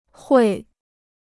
汇 (huì): to remit; to converge (of rivers).